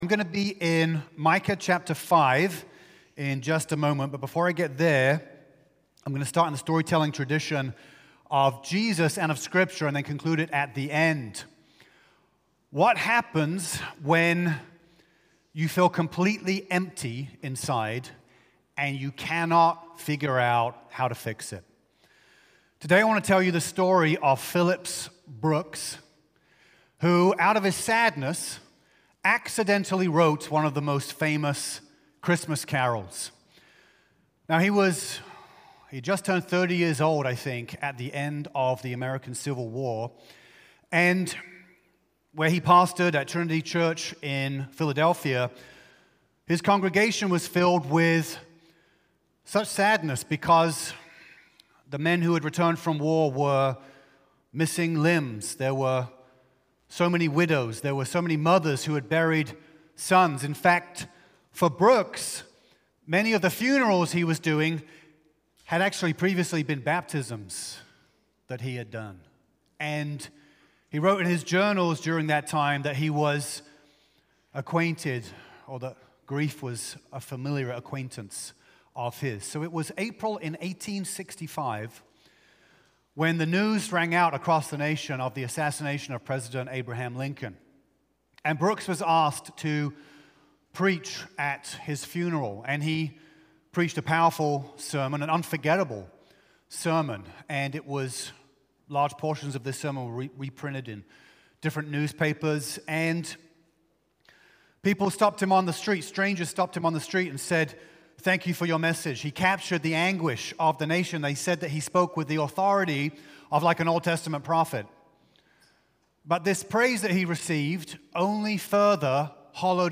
DEC-7-Full-Sermon.mp3